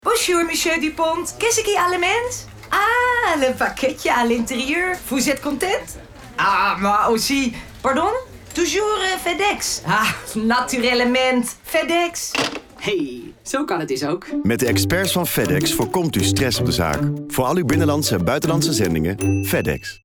Commercials: